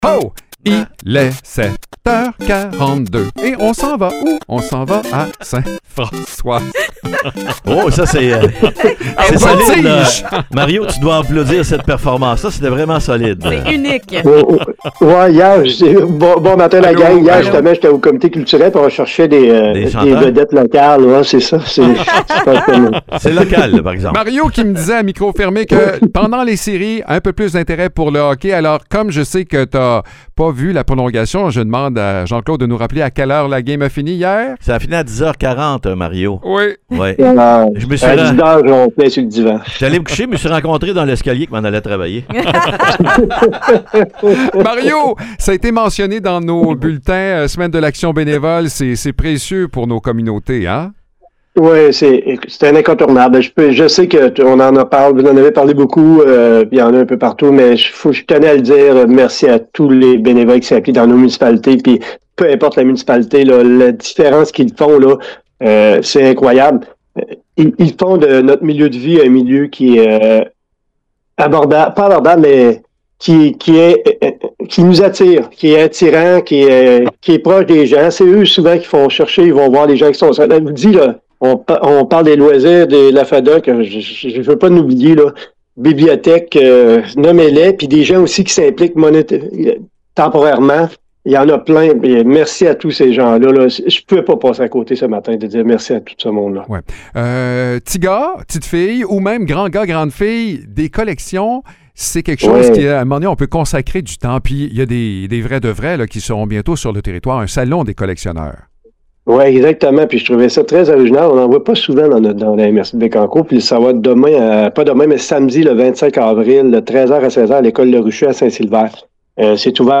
Mario Lyonnais, maire de Sainte-Françoise et préfet de la MRC de Bécancour, prend un moment pour remercier les bénévoles qui enrichissent et dynamisent nos communautés. Il en profite aussi pour lancer une invitation à une sortie originale : un rendez-vous pour partir à la découverte de collections uniques et passionnantes d’ici.